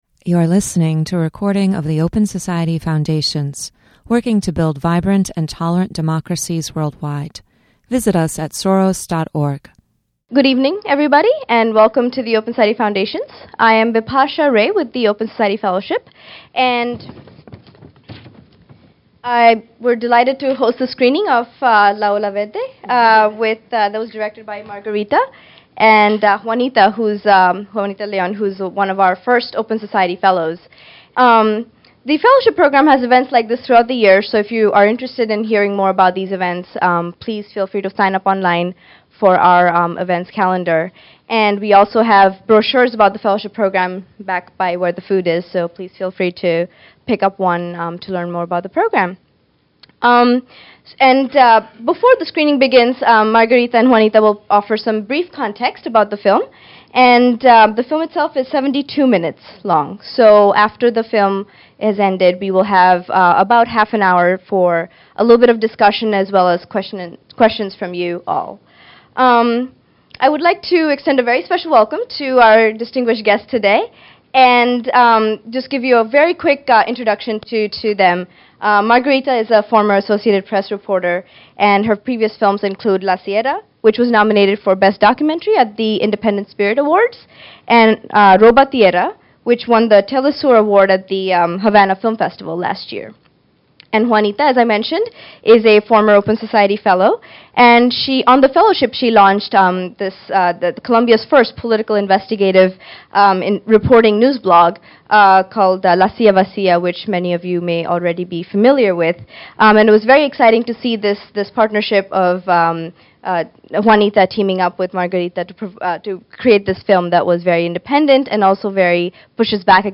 ‘La Ola Verde’ (Antanas’s Way): A Conversation with the Filmmakers